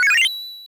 match-confirm.wav